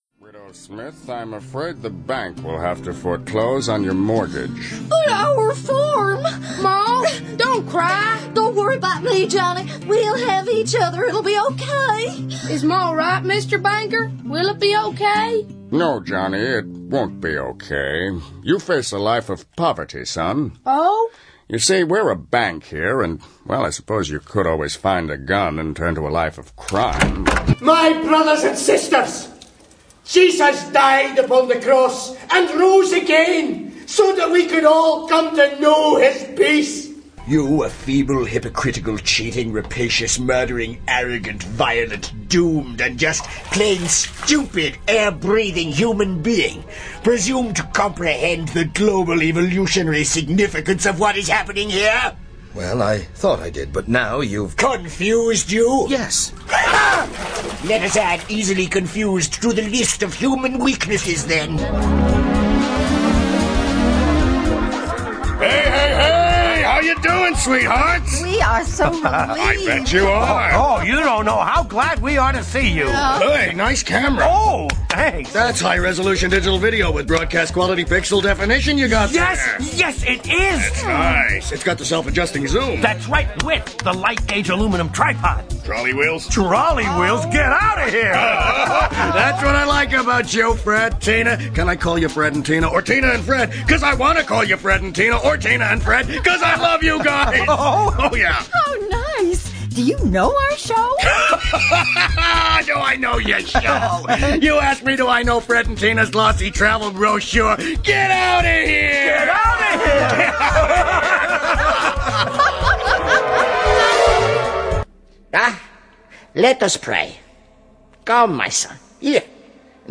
Gaming Voiceover & Animation Voiceover Dublin, Ireland
Audio-Theatre-Reel.wav